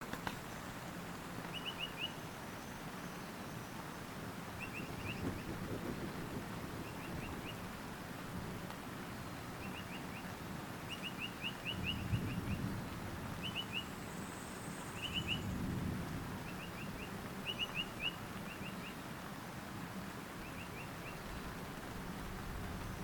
Tuquito Chico (Legatus leucophaius)
Nombre en inglés: Piratic Flycatcher
Condición: Silvestre
Certeza: Observada, Vocalización Grabada